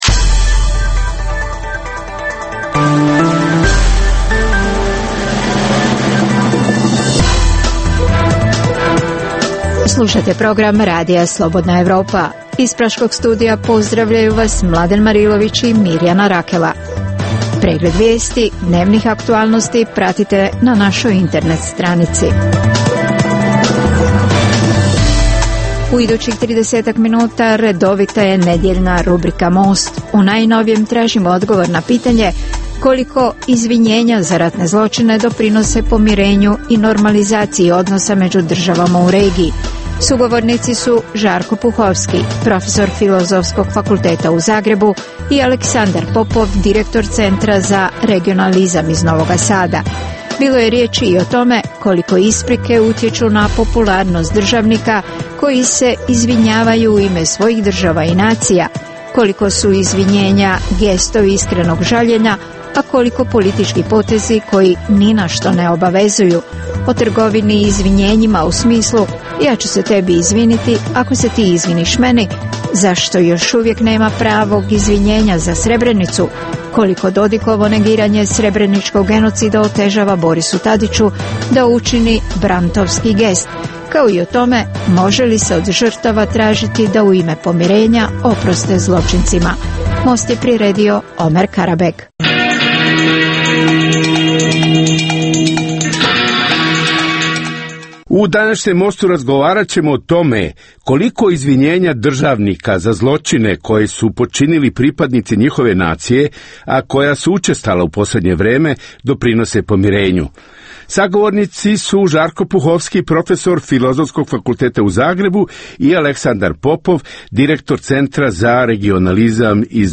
u kojem ugledni sagovornici iz regiona diskutuju o aktuelnim temama.